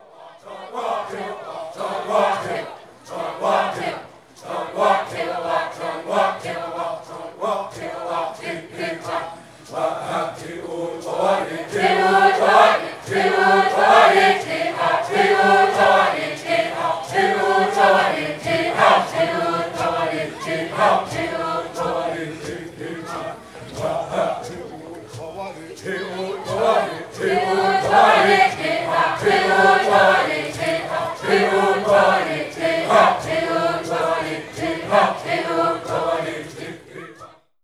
Uma canção do povo A’wé ou Xavante